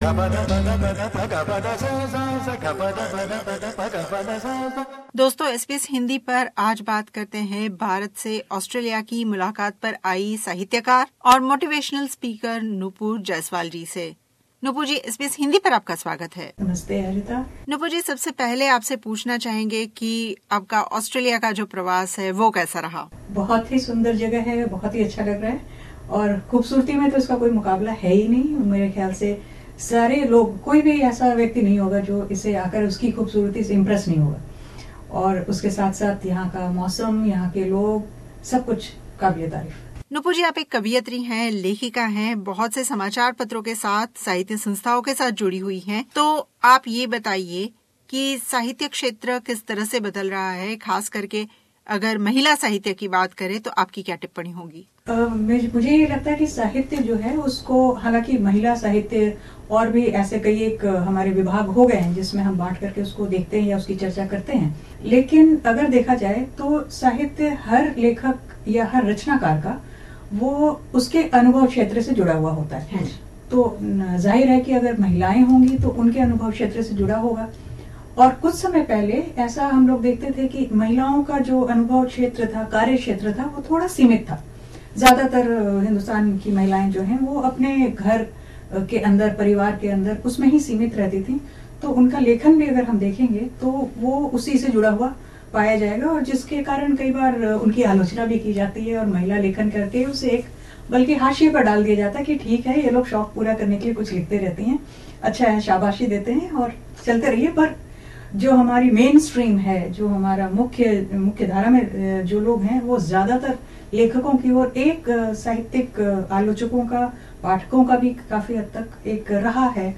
साहित्यक्षेत्रमे इंटरनेट और डिजिटल प्लेटफॉर्मके बढ़ते उपयोग पर उनका कहना था के साहित्यके लिए ये प्रवाह लाभदायी और घातक दोनों हो सकता है. किसीभी लेखक या कविको अपनी रचना पड़कोटक पहुंचने से पहले एक बार खुद सपादक बनकर जांचनी चाहिए. इस बी इस हिंदी के श्रोतामित्रोंके लिए उन्होंने अपनी दो कविताओंका पठन भी किया .